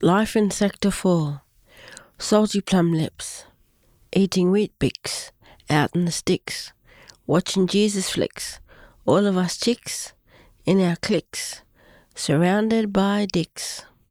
It is with great excitement that we share this collection of poems – both written and read – with Griffith Review and its readers.